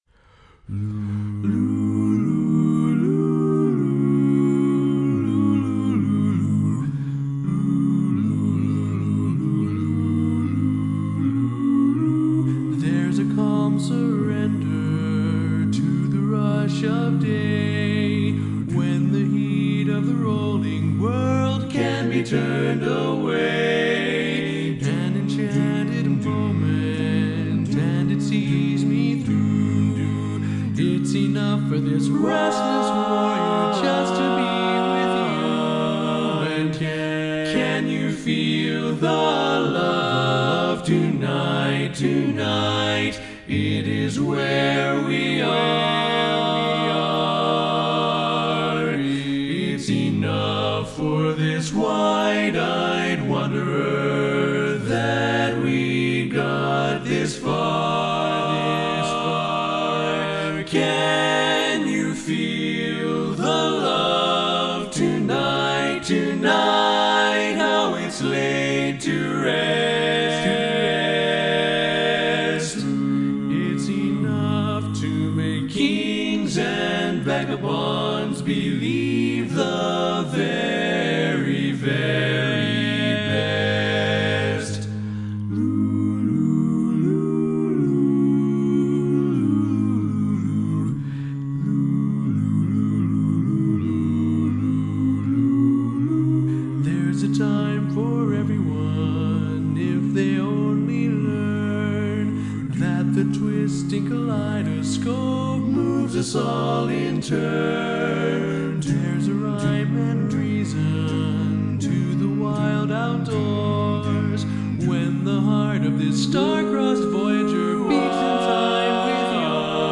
Kanawha Kordsmen (chorus)
Up-tempo
A♭Major